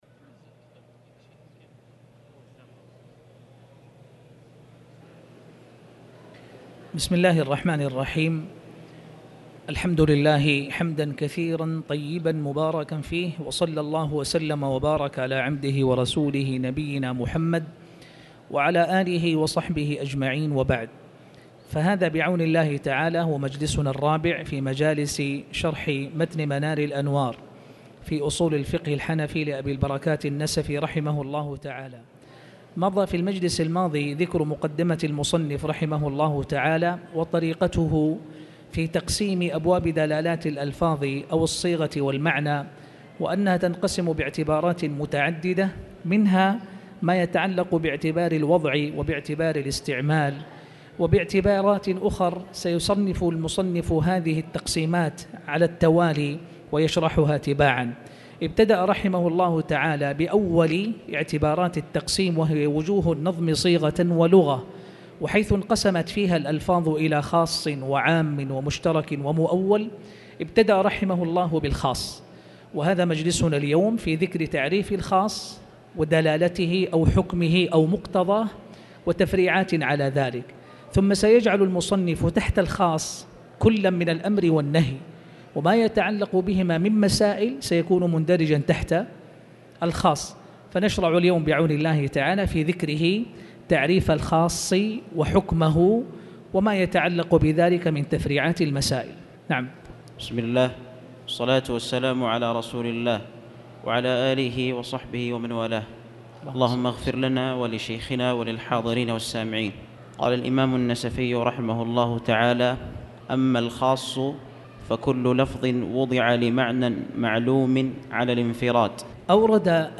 تاريخ النشر ٥ صفر ١٤٣٩ هـ المكان: المسجد الحرام الشيخ